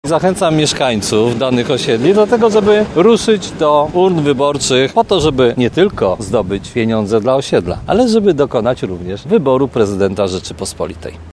Prezydent Tarnobrzega, Dariusz Bożek powiedział dziś podczas konferencji prasowej, że pomysł przyznania promes w nagrodę za wysoką frekwencję w wyborach zrodził się podczas ostatniego spotkania z przewodniczącymi tarnobrzeskich osiedli.